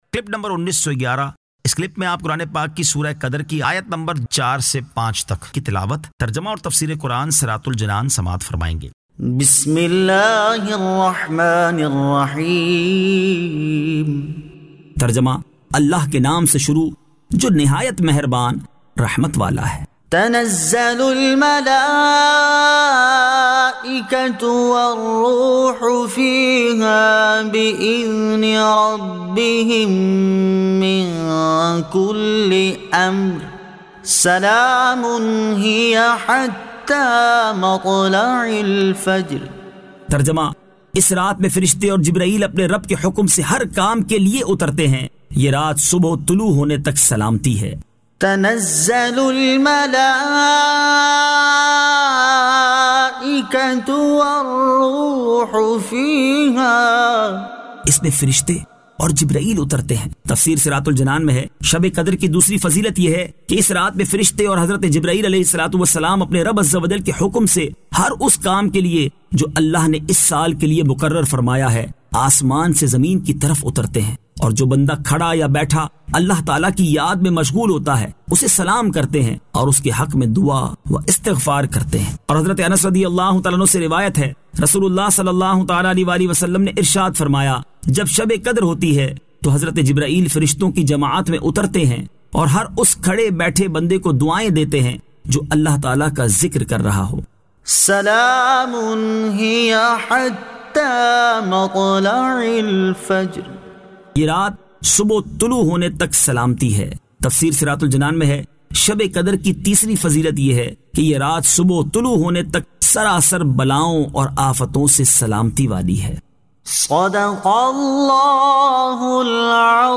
Surah Al-Qadr 04 To 05 Tilawat , Tarjama , Tafseer